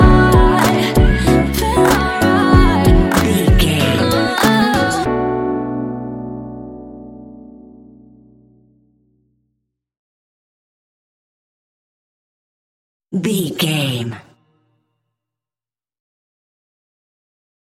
Ionian/Major
B♭
chilled
laid back
Lounge
sparse
new age
chilled electronica
ambient
atmospheric
morphing
instrumentals